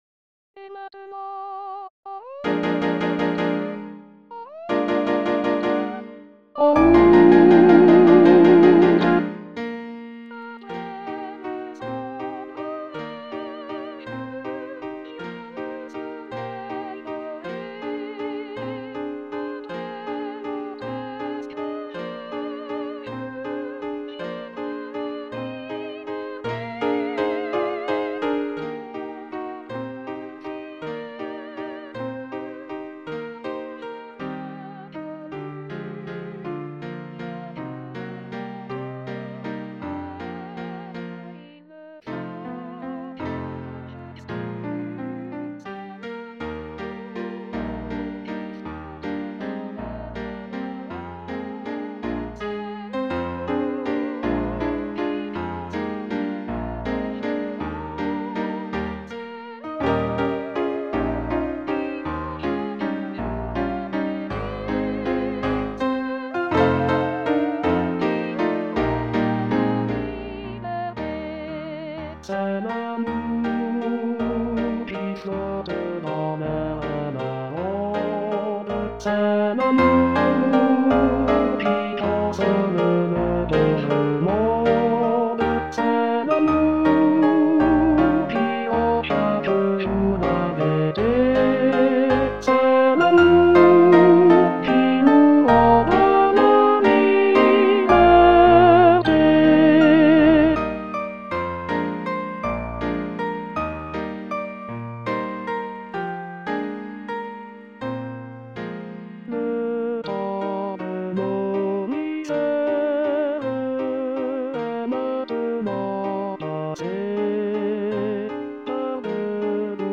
GANNE-Cest-lamour-Tenor.mp3